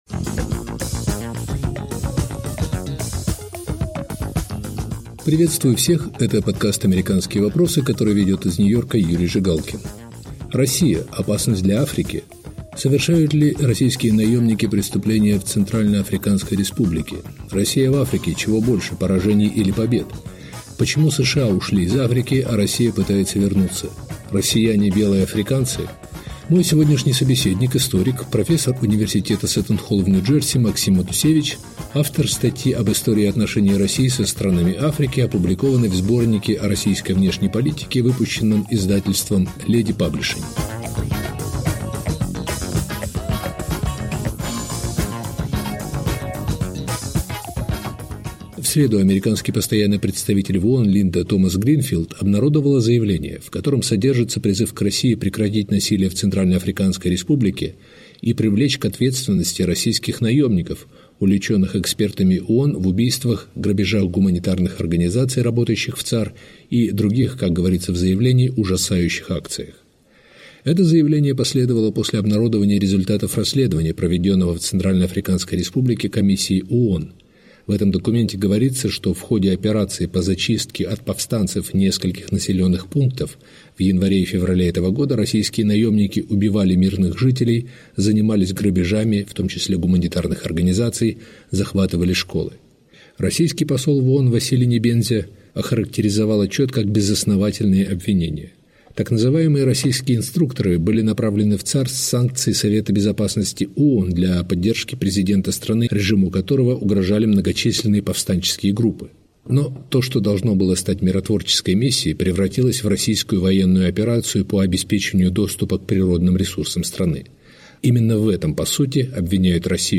Мой собеседник – историк